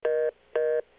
hangup.wav